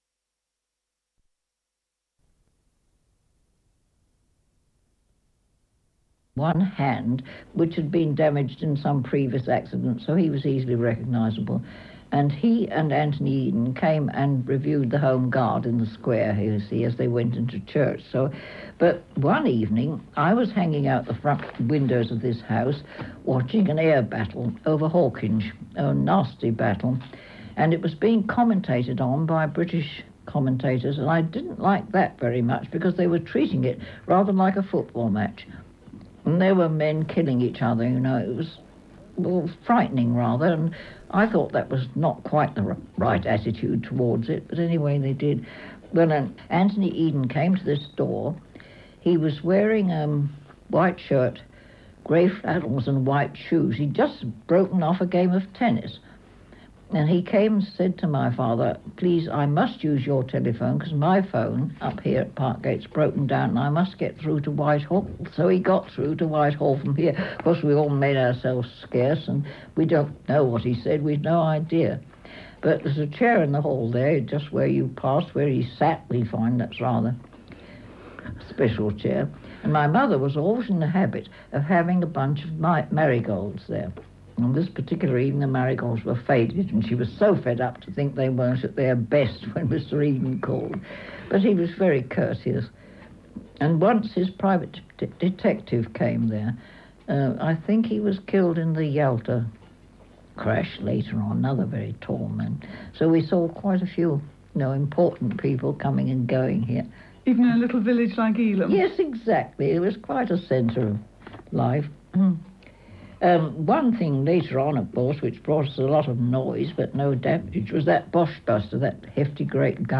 The interview is in two parts as the cassette tape needed to be changed!
Interview Part 2 (3.5mins.)